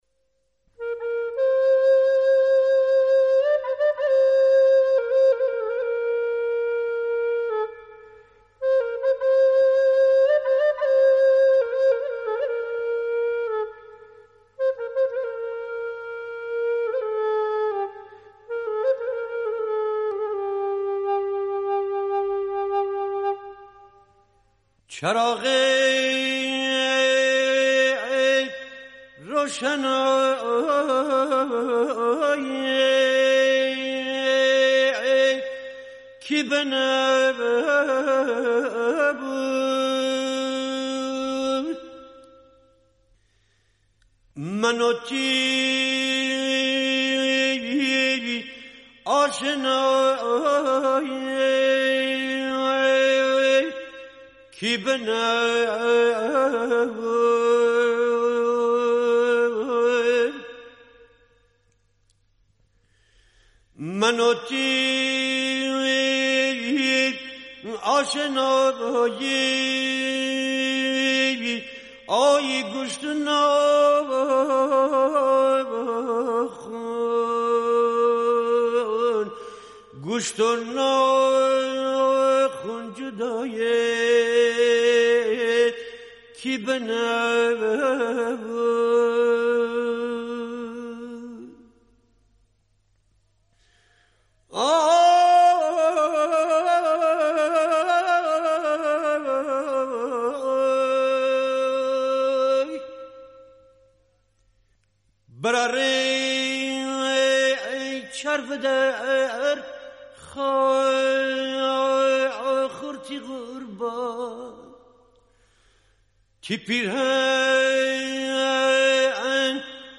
ترانه گیلکی